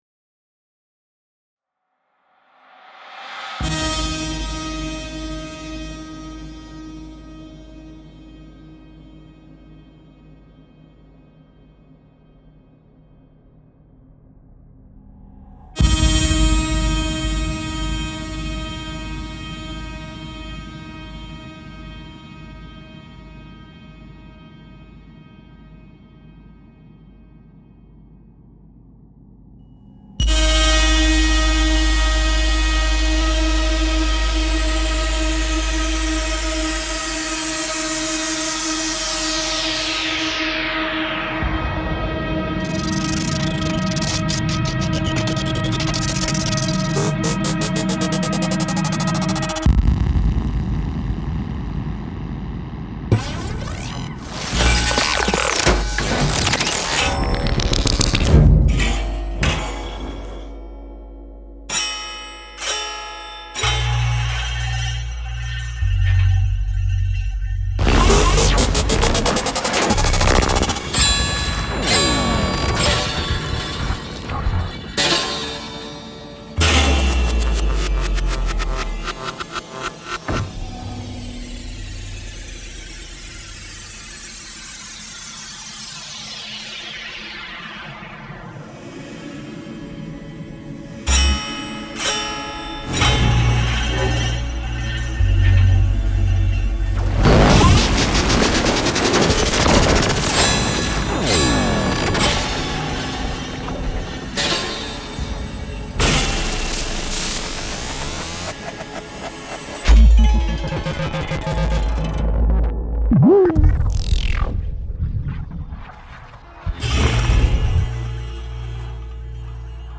A work focused on sounds hybridisation, rhizomatic writing, micromontage and articulation of musical discourse. The sounds were created by using gamelan percussions, modular synthesizers and digital processing.